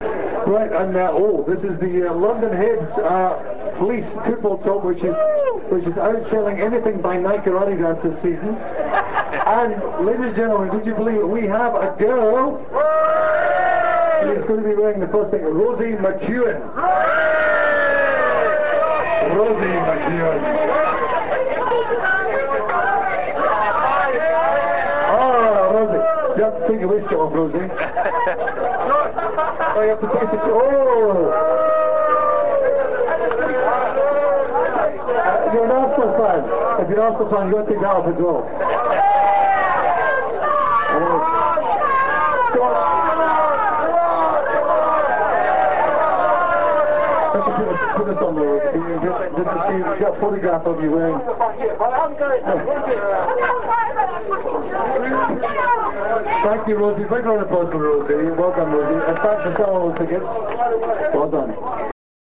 London Hibs Annual Burns Night Supper was held on Saturday 22nd January 2000 at the Kavanagh's Pub, Old Brompton Road.